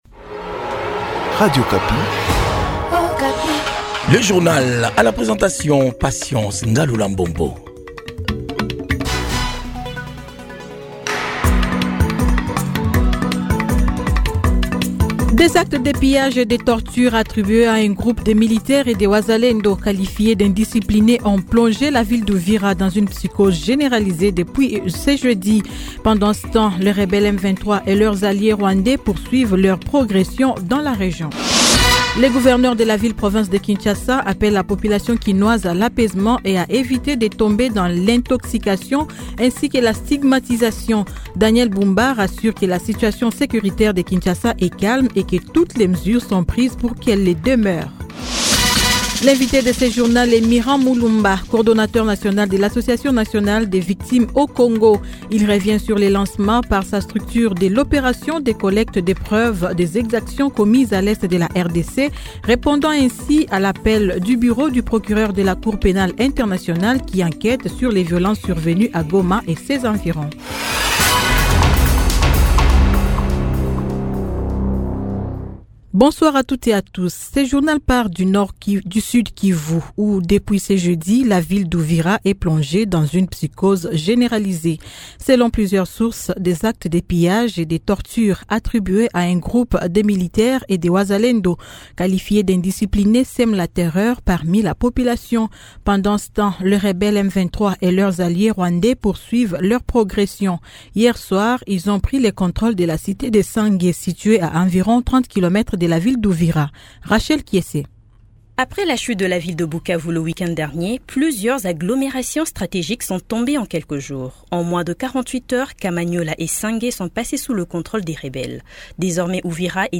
Journal Soir 18h